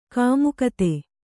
♪ kāmukate